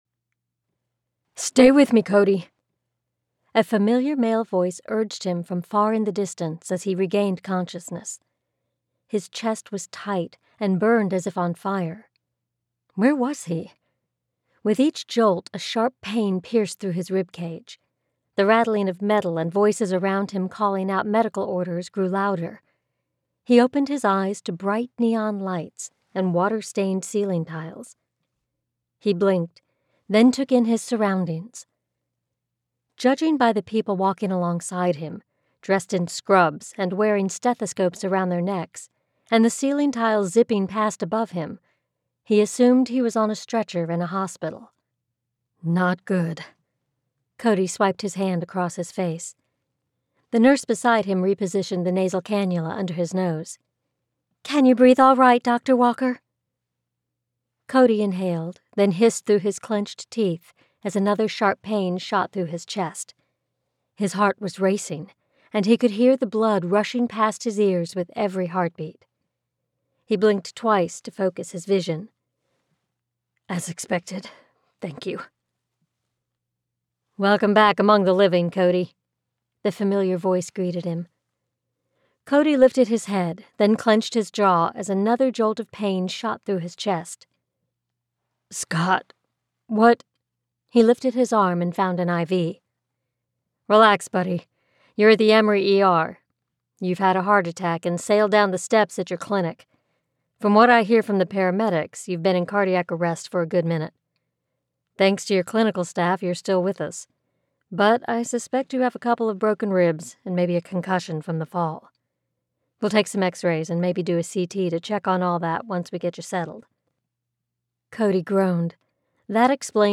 Twist of Fate Audiobook 🎧 Just Released